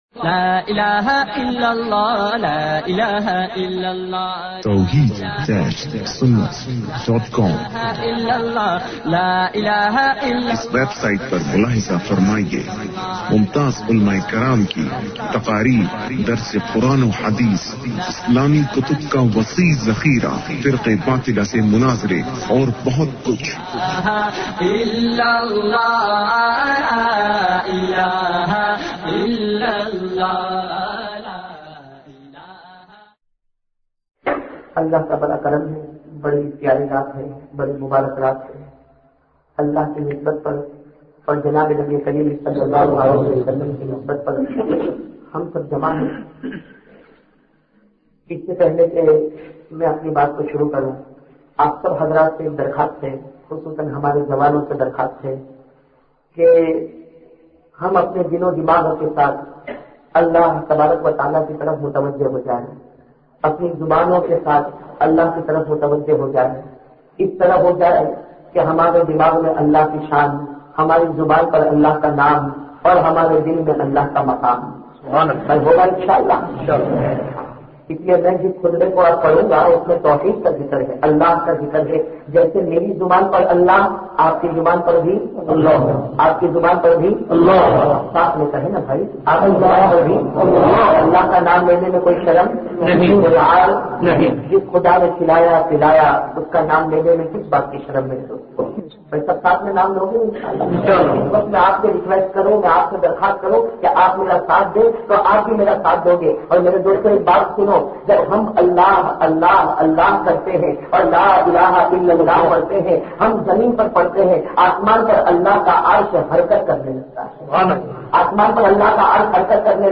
Azmat e Nabi Maqam e Nabi bayan mp3